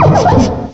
cry_not_swadloon.aif